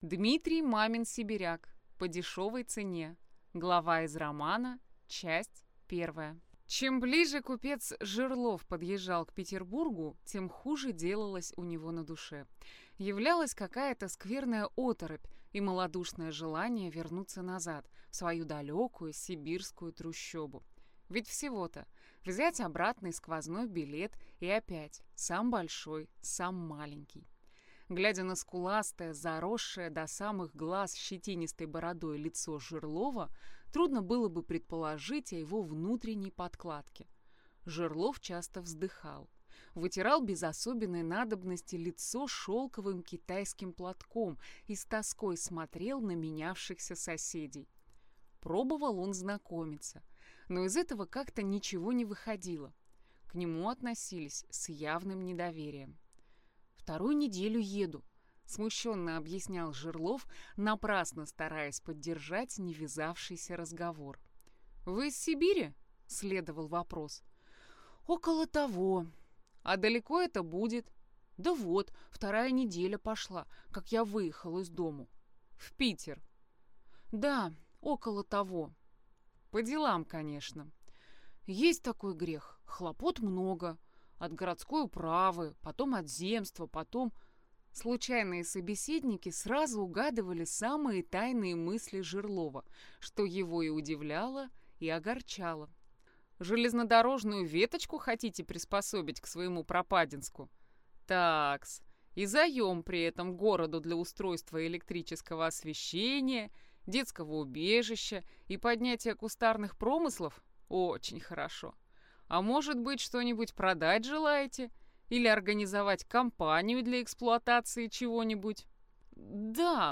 Аудиокнига По дешевой цене | Библиотека аудиокниг
Прослушать и бесплатно скачать фрагмент аудиокниги